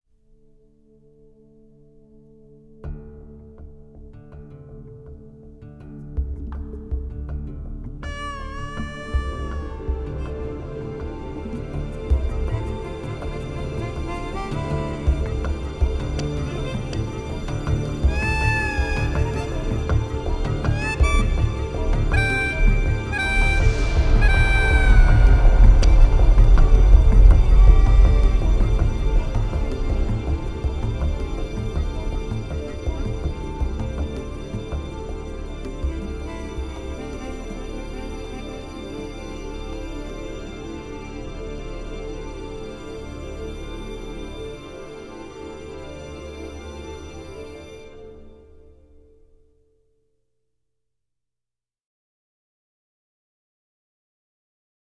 BSO